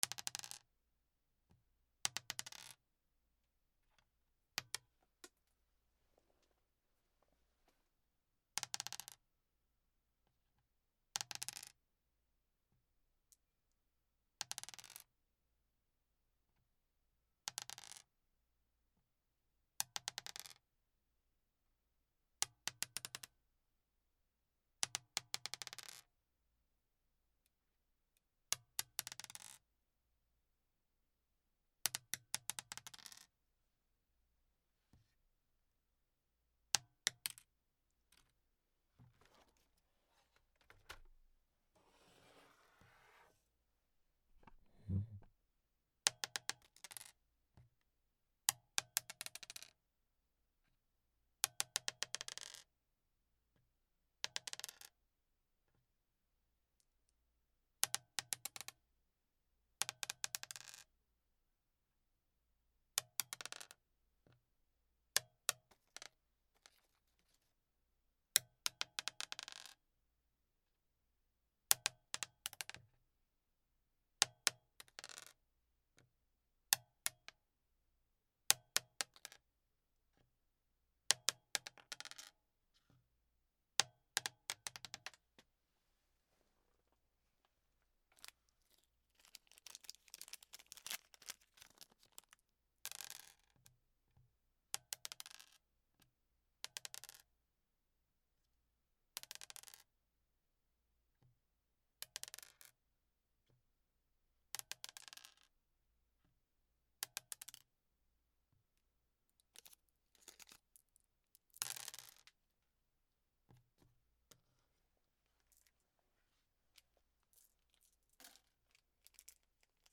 サイコロ
机の上 D50